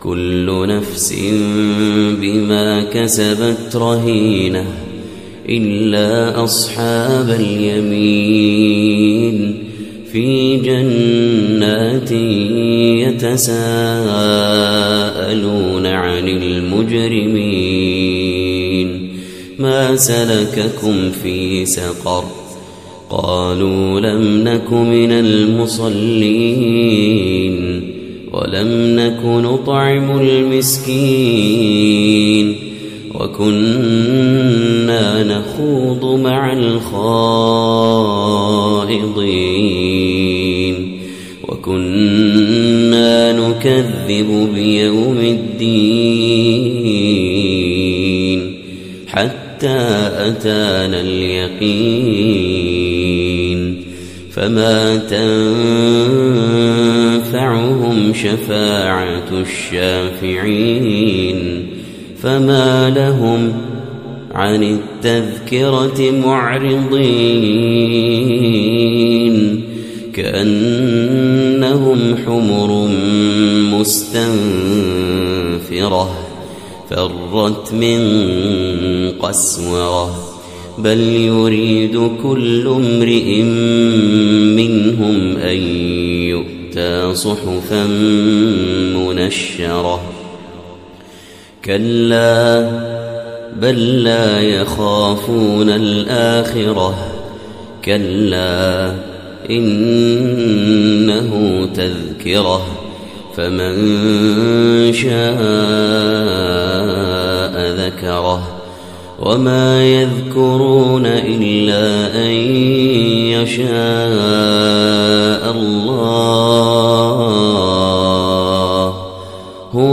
تلاوة فجرية ندية من سورة المدثر
مسجد الدولة الكبير ، الكويت